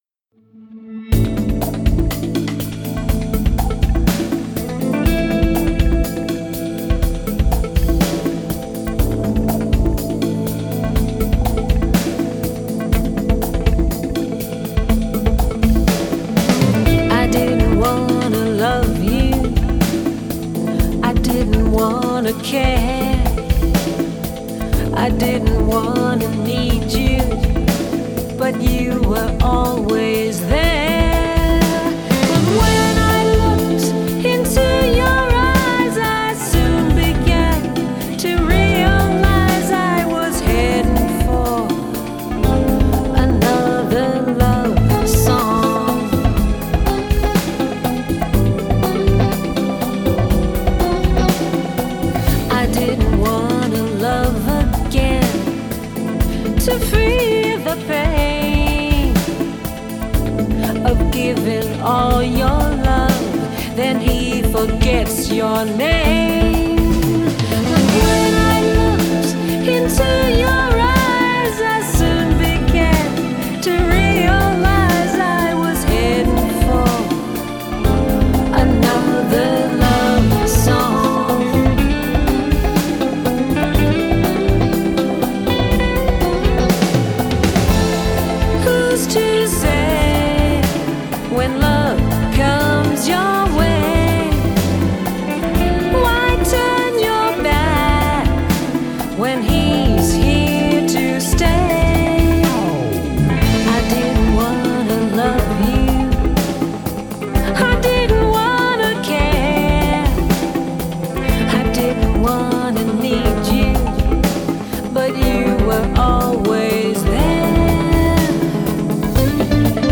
Genre: Jazz/Pop Vocals